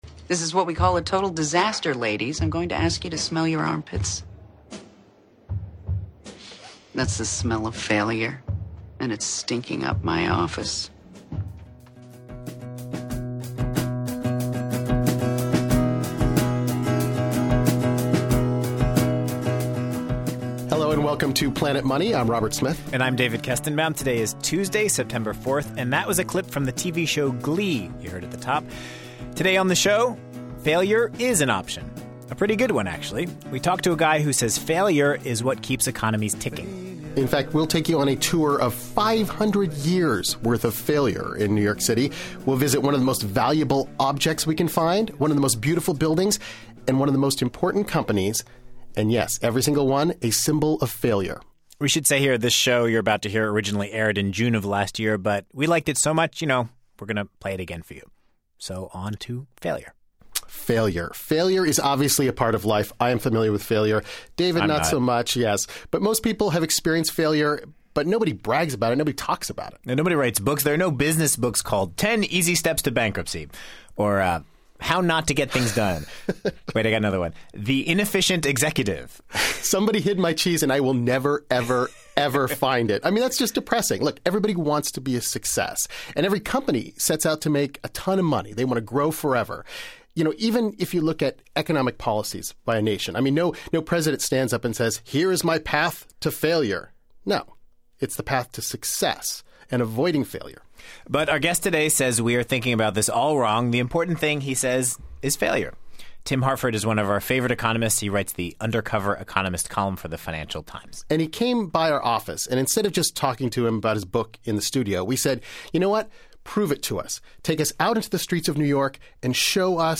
On today's show, we hit the streets of Manhattan with economist Tim Harford.